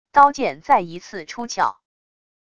刀剑再一次出鞘wav音频